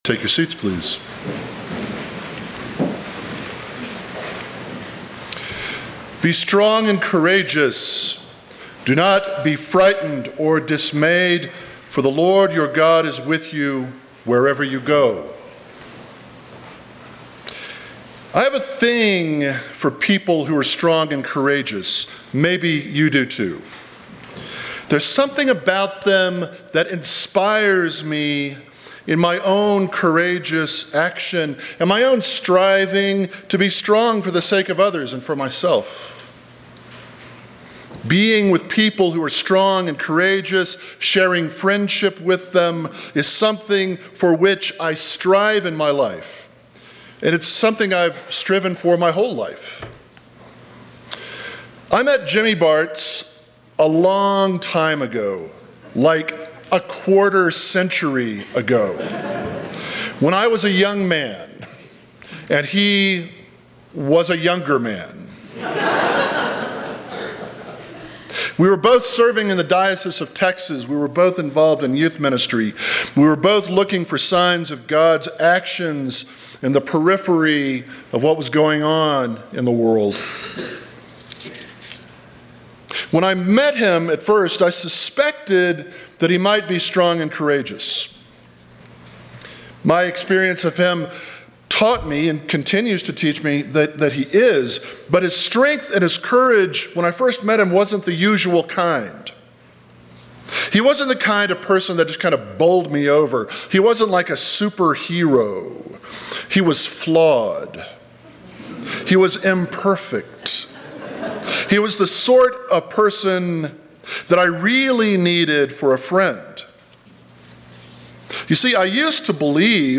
Sermon from St. John's Jackson Hole.